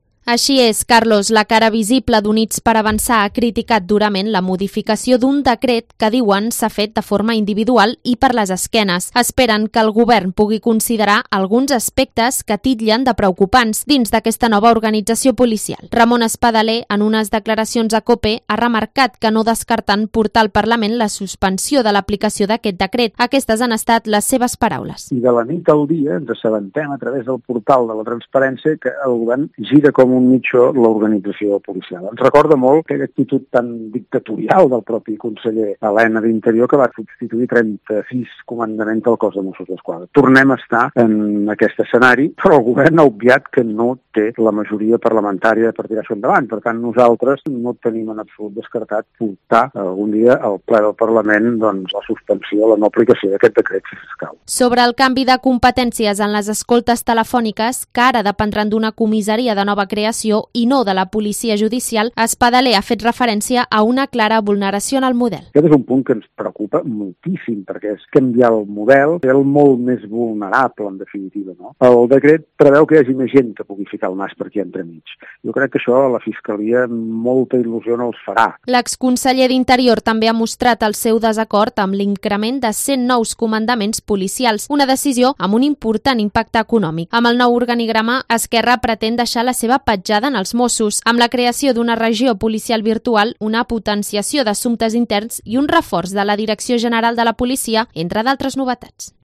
crónica sobre el nuevo decreto de organización de los Mossos d'Esquadra